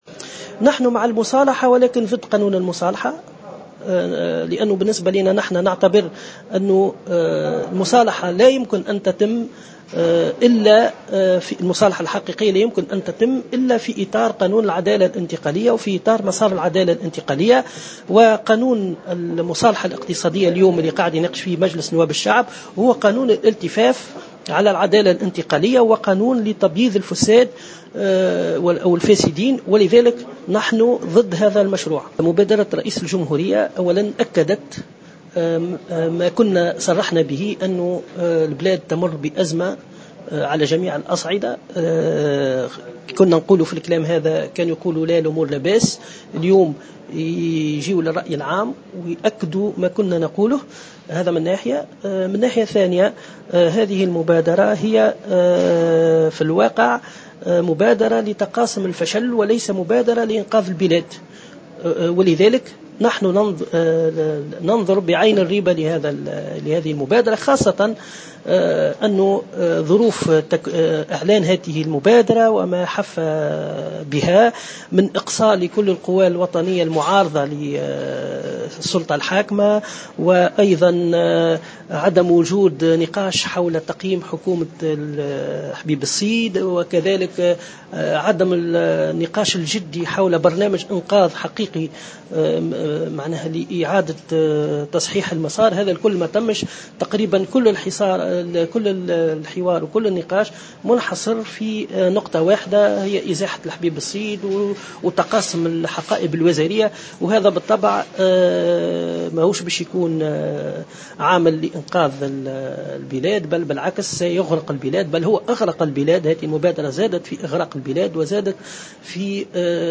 وقال في تصريح لمراسل "الجوهرة أف أم" إن الحزب مع المصالحة لكنه ضد قانون المصالحة الذي يتم مناقشته حاليا بمجلس نواب الشعب، مشيرا إلى أن المصالحة الحقيقة لا يمكن أن تتم إلا في إطار مسار العدالة الانتقالية، وفق تعبيره.